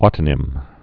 tə-nĭm)